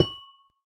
Minecraft Version Minecraft Version 1.21.5 Latest Release | Latest Snapshot 1.21.5 / assets / minecraft / sounds / block / amethyst / place3.ogg Compare With Compare With Latest Release | Latest Snapshot